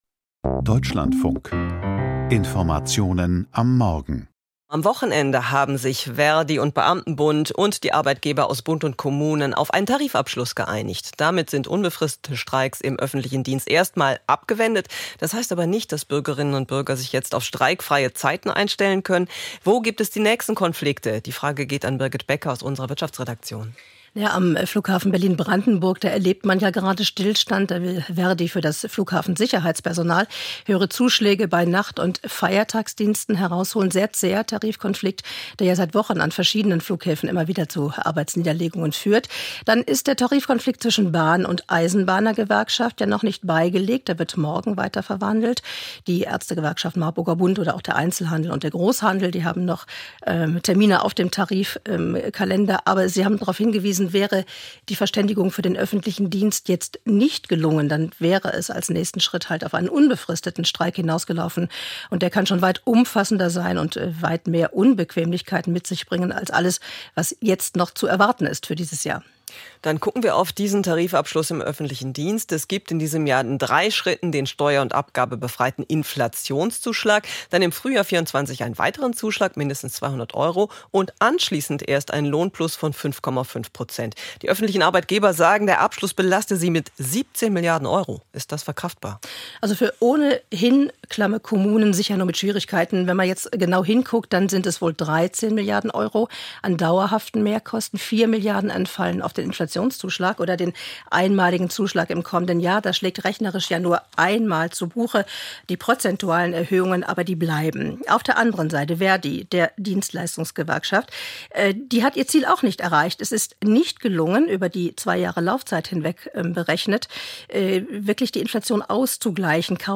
Wirtschaftsgespräch. Folgen der Tarifeinigung im öffentlichen Dienst